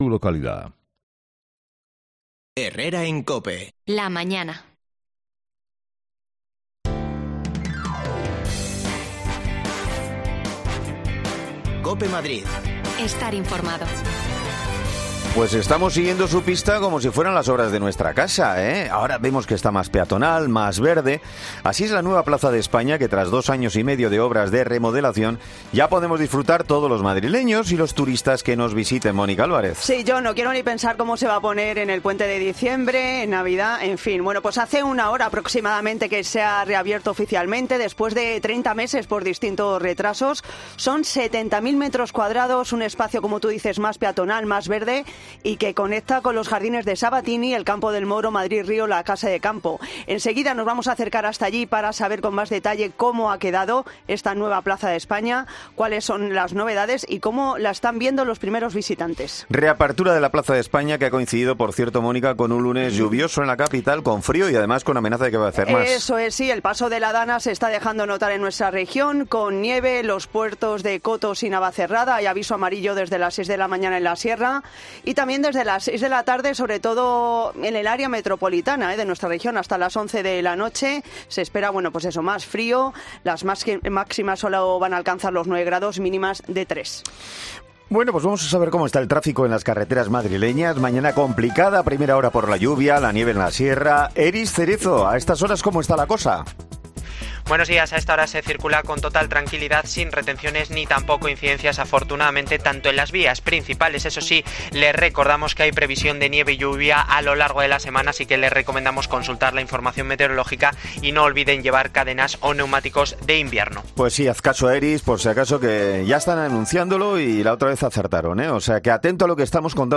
AUDIO: La nueva Plaza de España ya está abierta a todos los madrileños. Nos damos un paseo por este nuevo espacio más peatonal y más verde
Las desconexiones locales de Madrid son espacios de 10 minutos de duración que se emiten en COPE , de lunes a viernes.